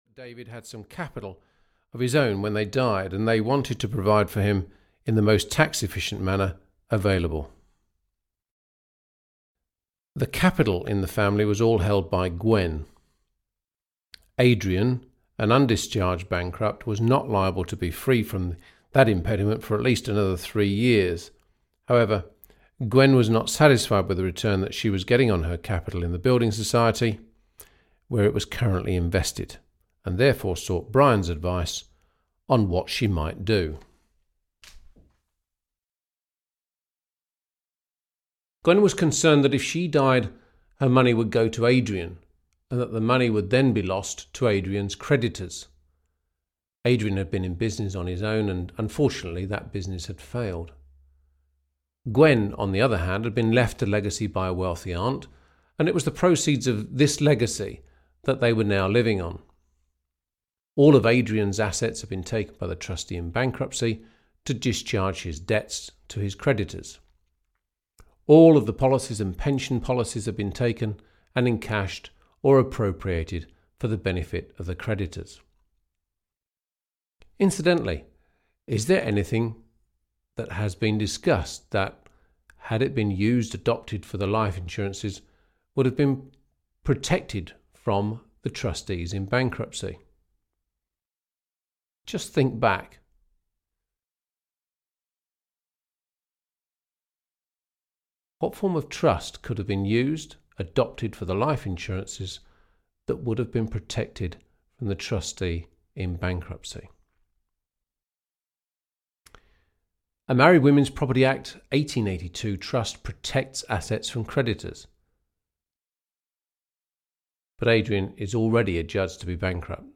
Trusts - A Practical Guide 7 (EN) audiokniha
Ukázka z knihy